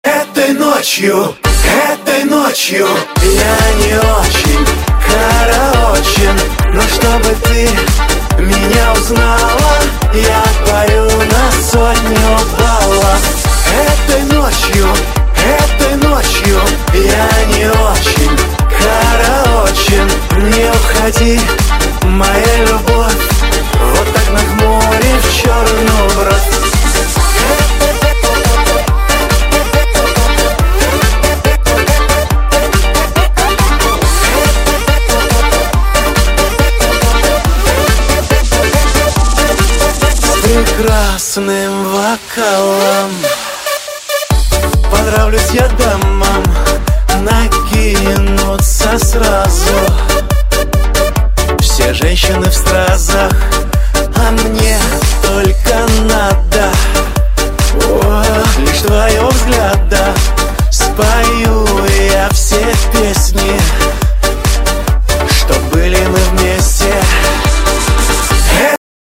• Качество: 128, Stereo
забавные
веселые